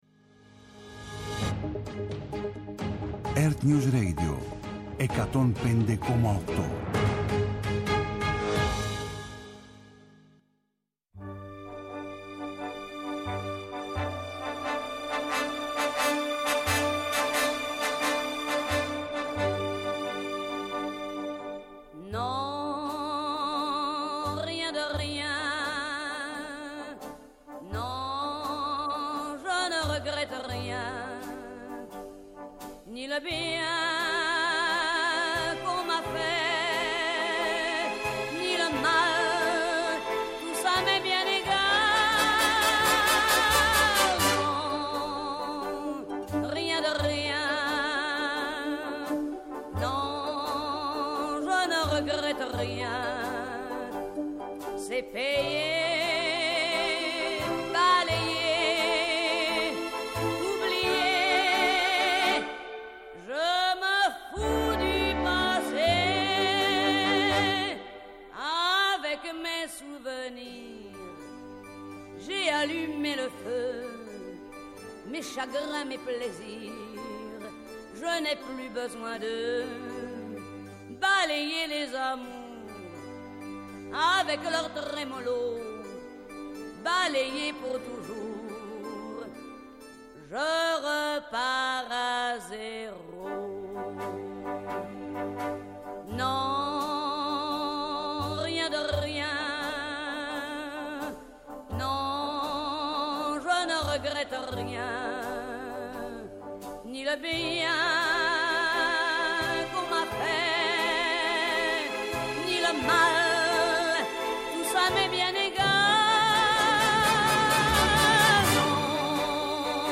Ενημερωτική εκπομπή